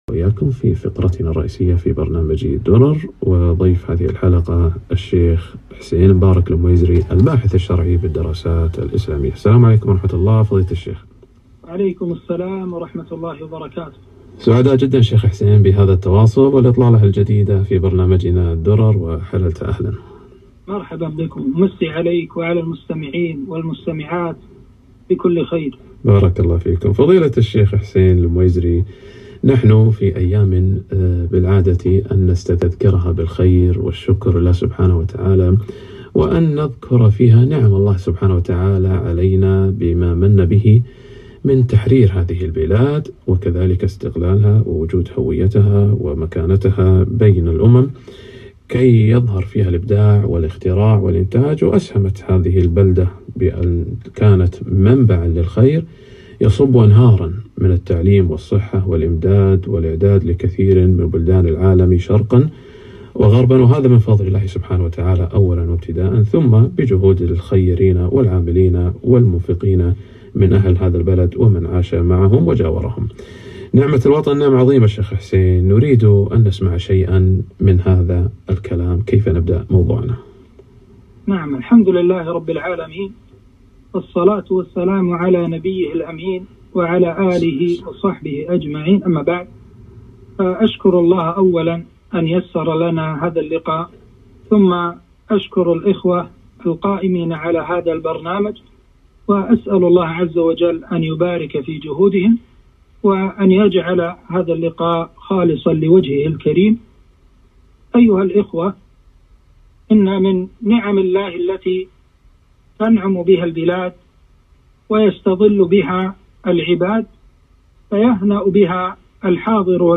نعمة الوطن وشكر المنعم - لقاء إذاعي